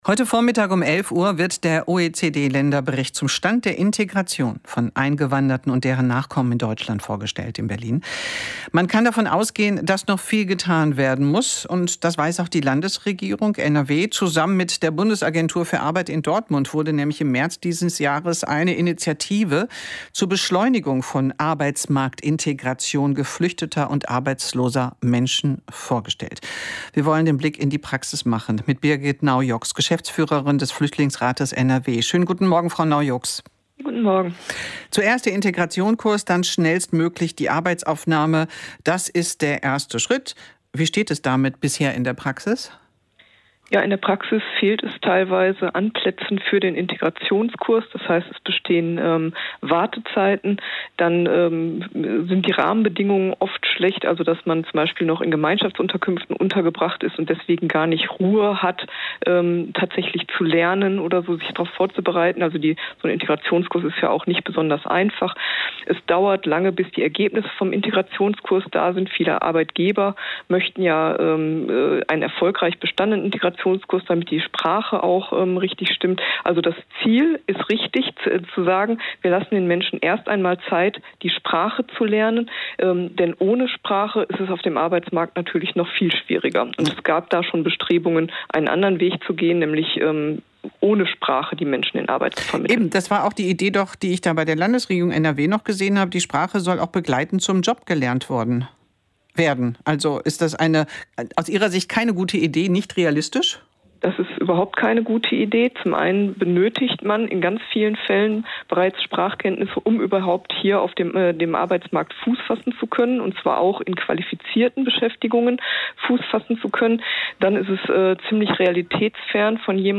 Beitrag im „Morgenecho“ auf WDR 5 vom 04.07.2024.
Den Mitschnitt des Interviews finden Sie